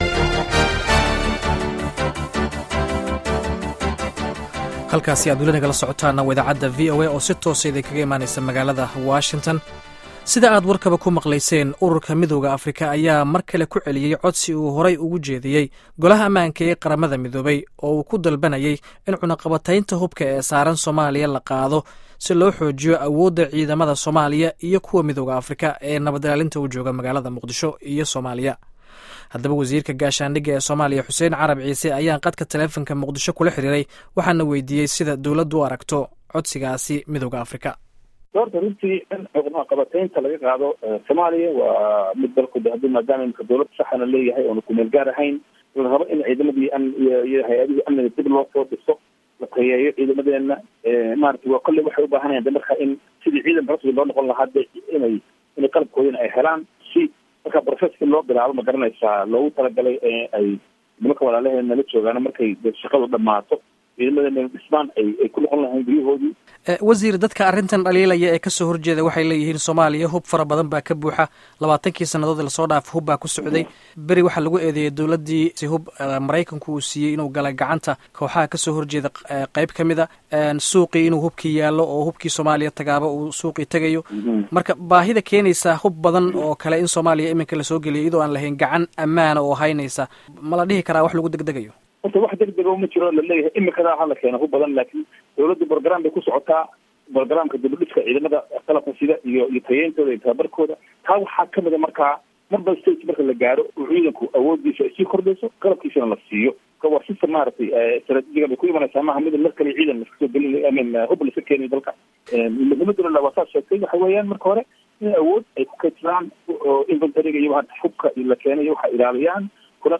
Dhageyso Wareysiga Wasiirka Gaashaandhigga